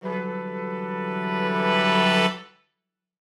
Index of /musicradar/gangster-sting-samples/Chord Hits/Horn Swells
GS_HornSwell-Emin+9sus4.wav